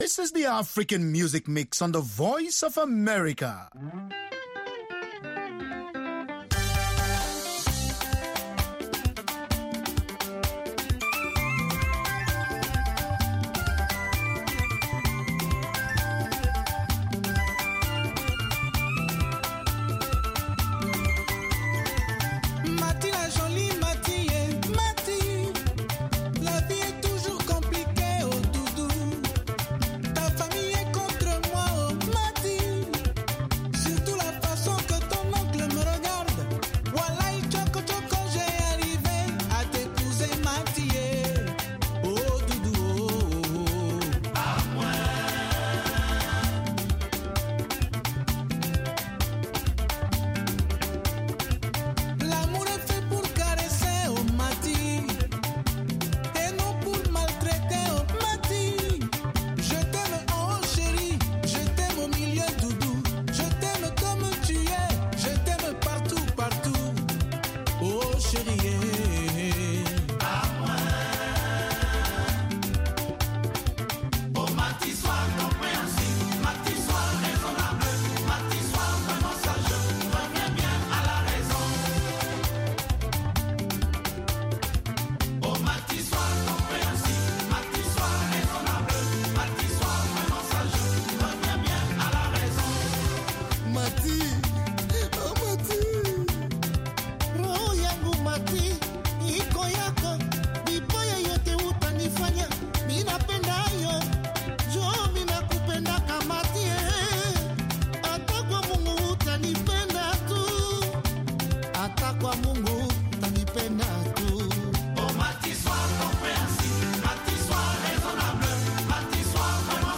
from N’dombolo to Benga to African Hip Hop
pan-African music